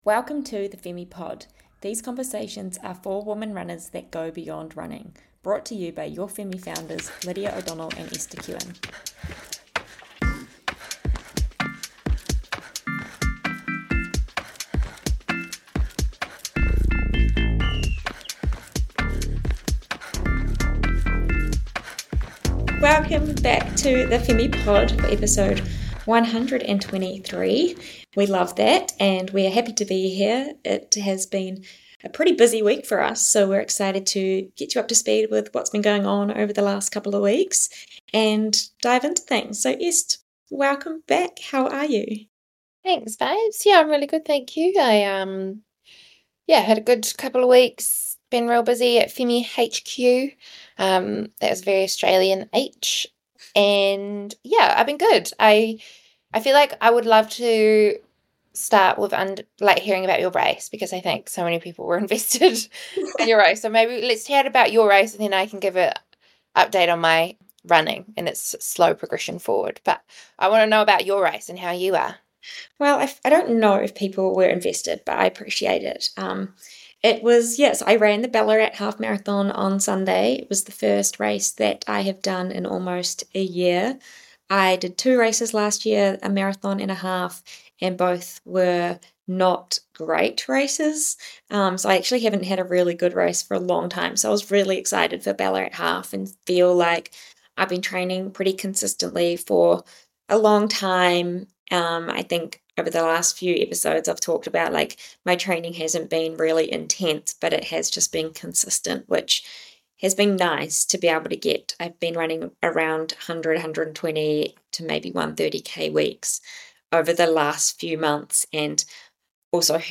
During this conversation we dive into the behind the scenes of one of the biggest and best running personalities online.